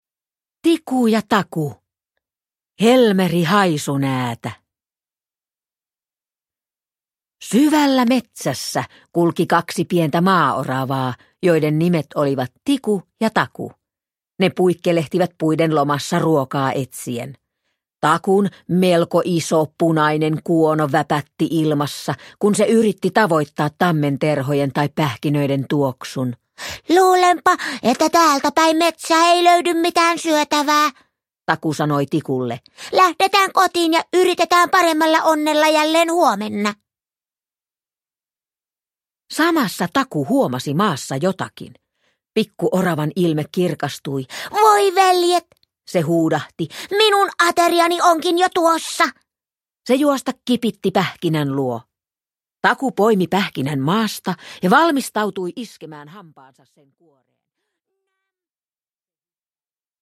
Tiku ja Taku. Helmeri Haisunäätä – Ljudbok – Laddas ner